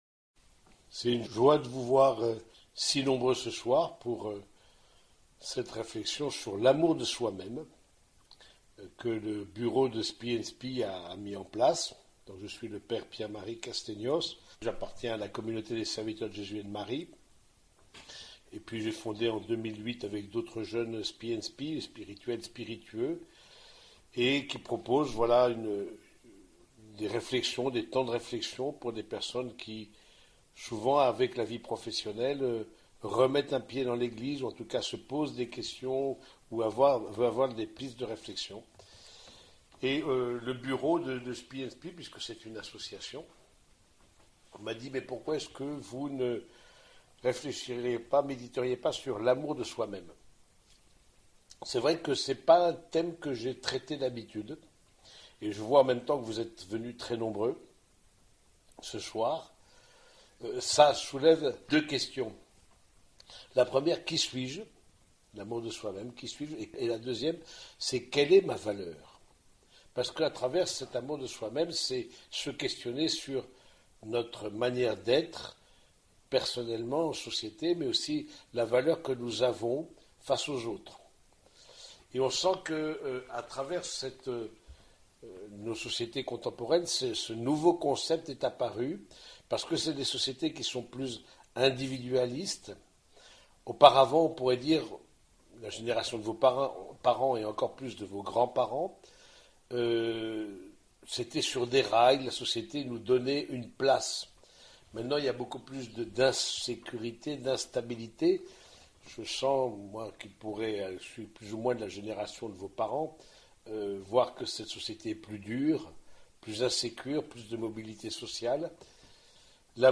Conférence Spi&Spi d’Avril 2024